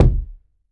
09 Kick BMB.WAV